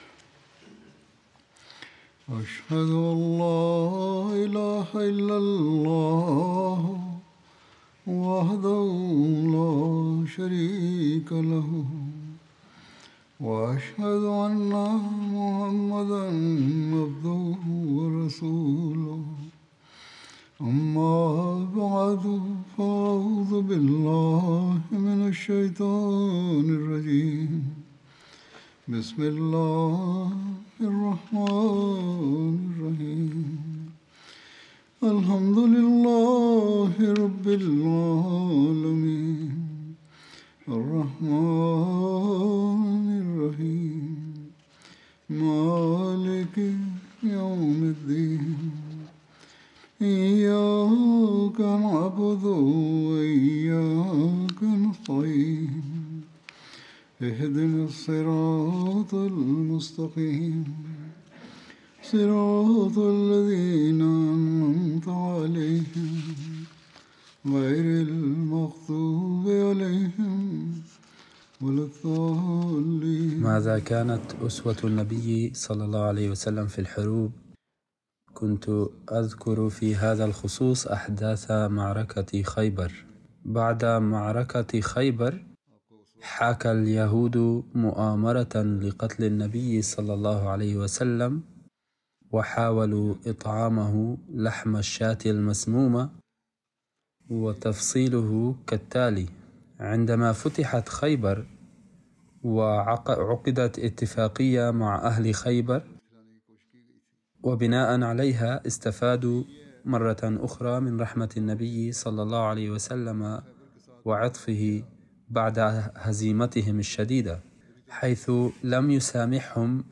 Arabic translation of Friday Sermon